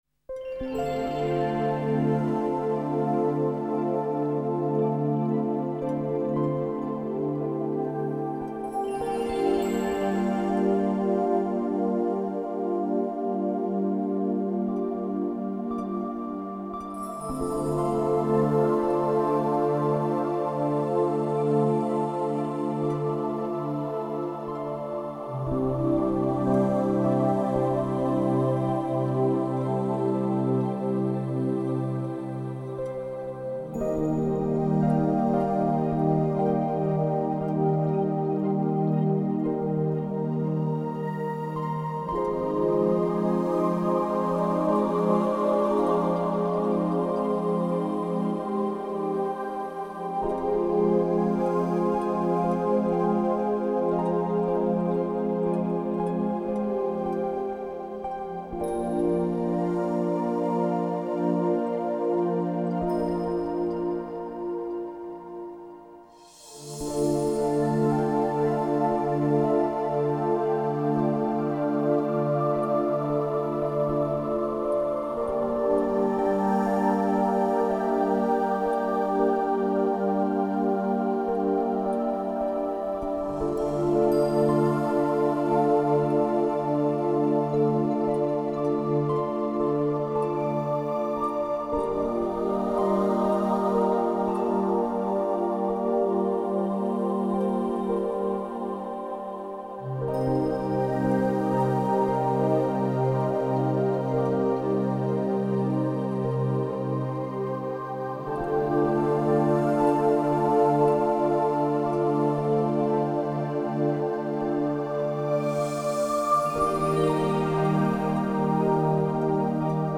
Znova hudba vhodná pre reiki, relaxáciu a meditáciu.